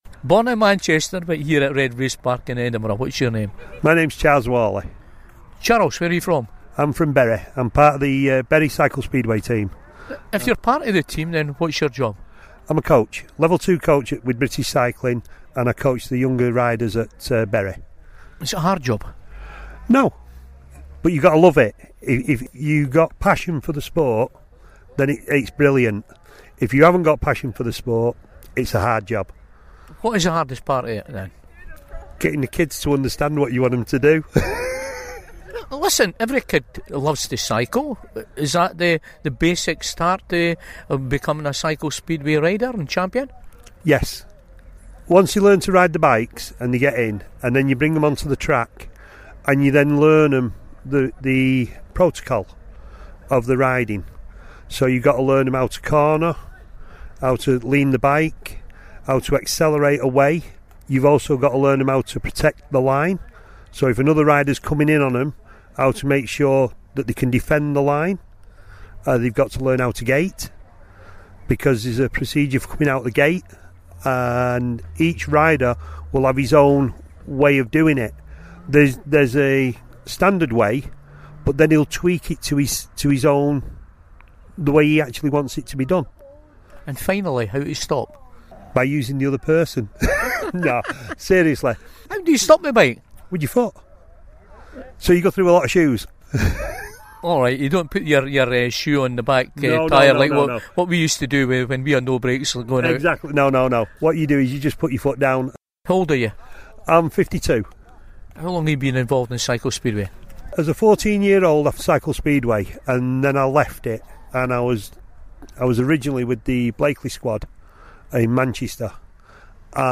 here at Redbraes Park in Edinburgh